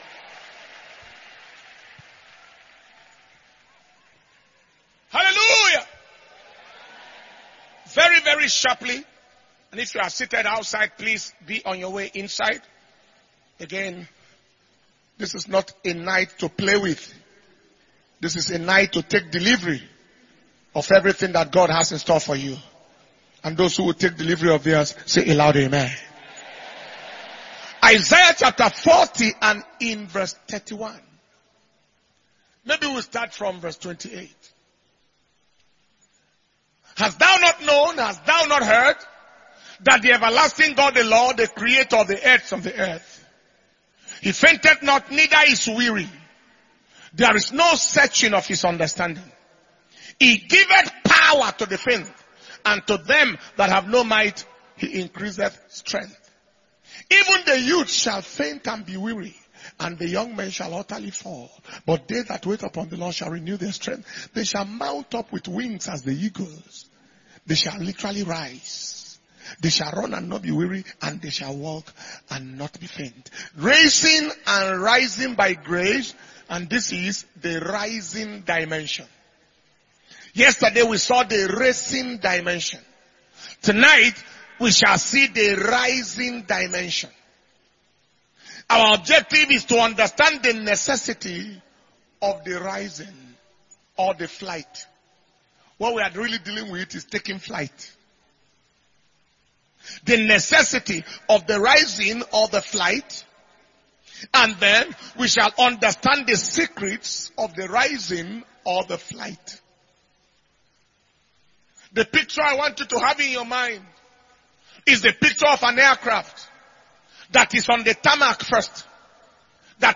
Messages